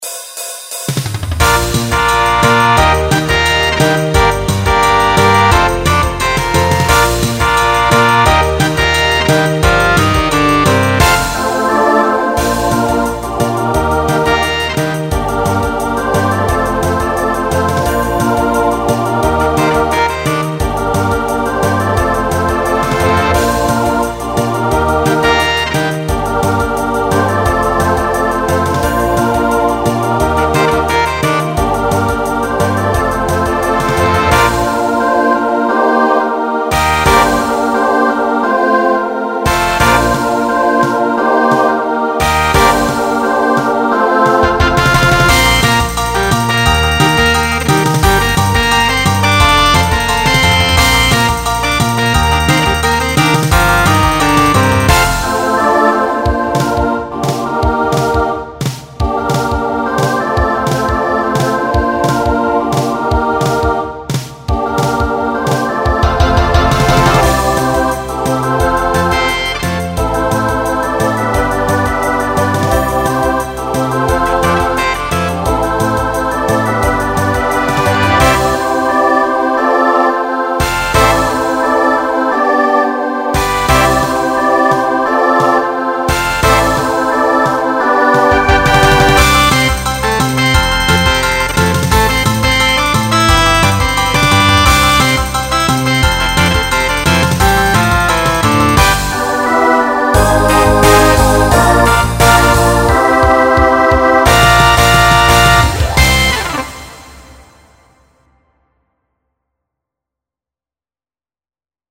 Genre Pop/Dance
Quick Hitter Voicing SATB